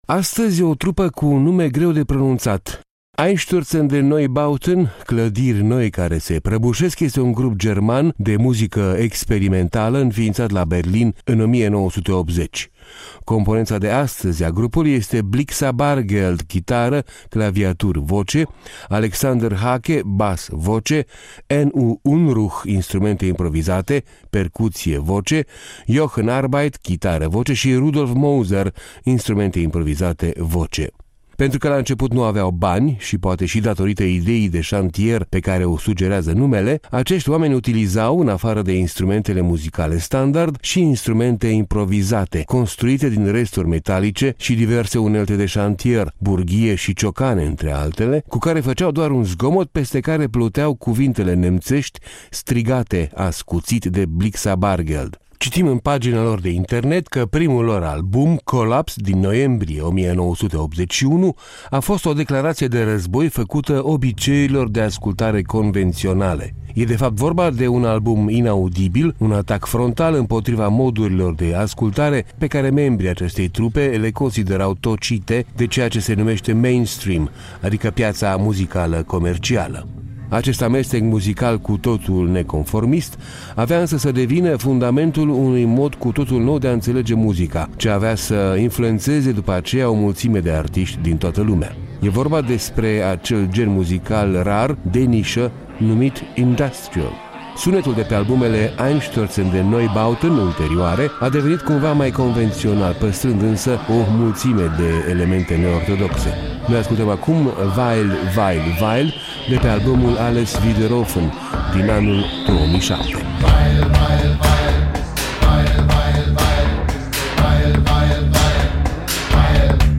Astăzi, o trupă cu un nume greu de pronunțat: Einstürzende Neubauten („clădiri noi care se prăbușesc”) este un grup german de muzică experimentală înființat la Berlin în 1980.
Pentru că la început nu aveau bani, și poate și datorită ideii de șantier pe care o sugerează numele, acești oameni utilizau - în afară de instrumentele muzicale standard - și instrumente improvizate, construite din resturi metalice și diverse unelte de șantier (burghie și ciocane, între altele), cu care făceau doar un zgomot peste care pluteau cuvintele nemțești strigate ascuțit de Blixa Bargeld.
E vorba de acel gen muzical muzical rar, de nișă, numit industrial.
Sunetul de pe albumele Einstürzende Neubauten ulterioare a devenit cumva mai convențional, păstrînd însă o mulțime de elemente „neortodoxe”.